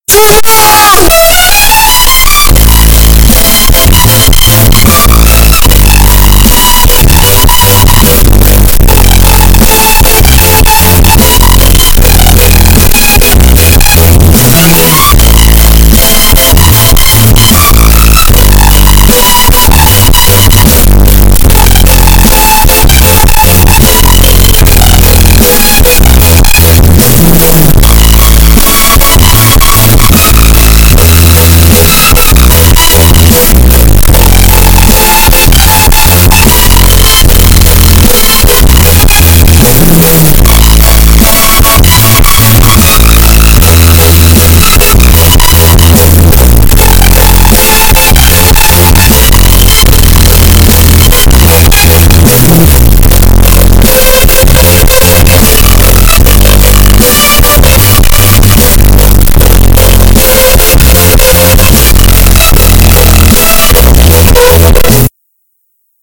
• Качество: 295, Stereo
громкие
мощные басы
очень громкие
OVER 200DB